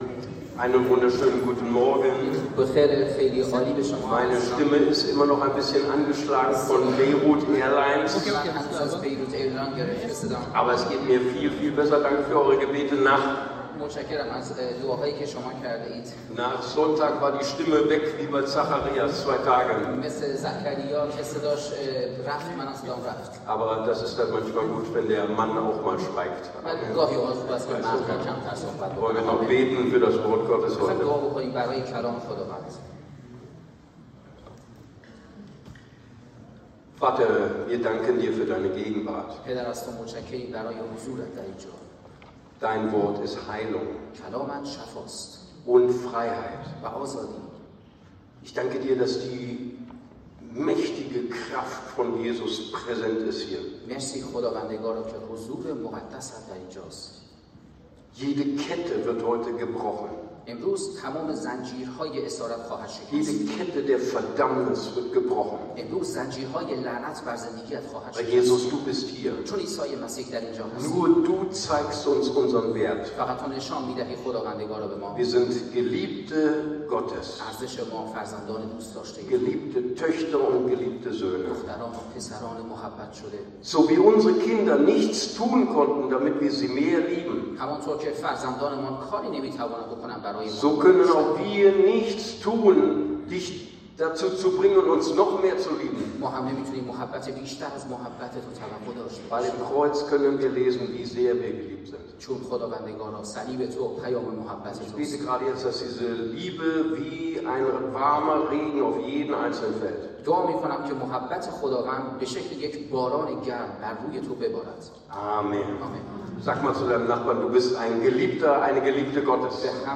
April 2025 55 Minuten ICB Predigtreihe Römerbrief Das „5.